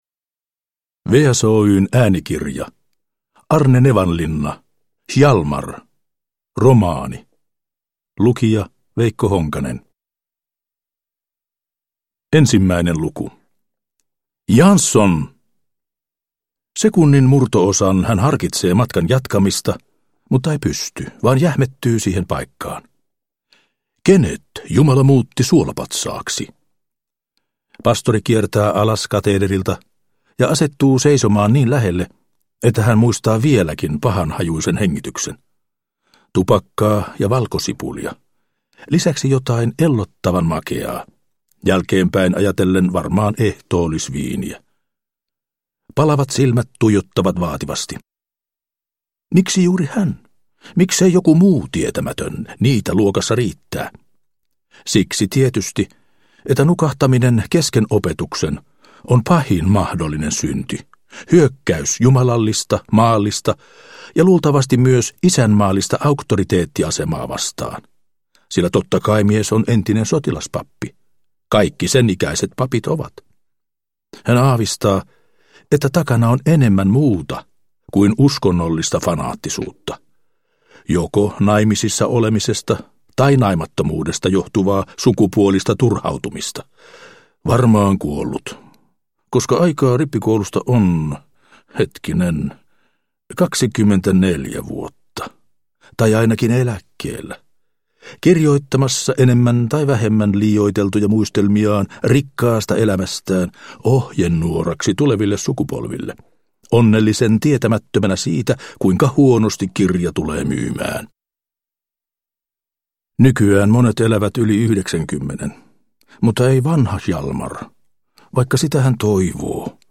Hjalmar – Ljudbok – Laddas ner